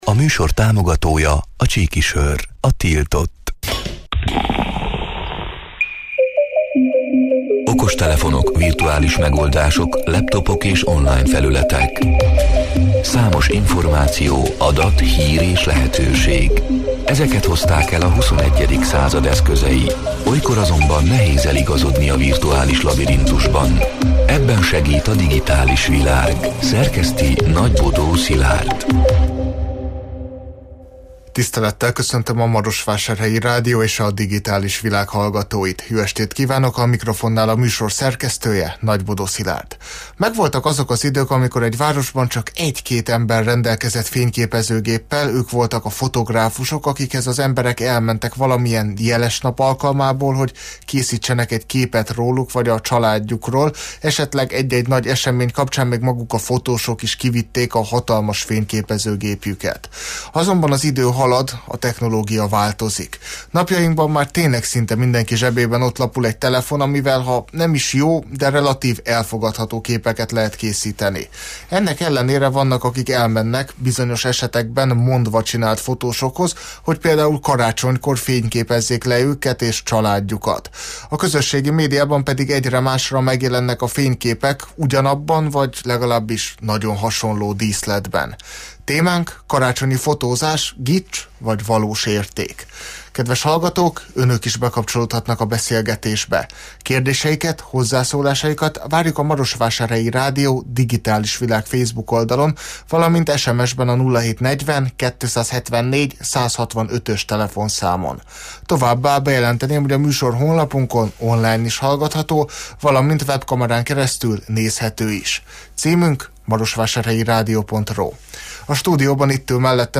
A Marosvásárhelyi Rádió Digitális Világ (elhangzott: 2024. december 17-én, kedden este nyolc órától élőben) c. műsorának hanganyga: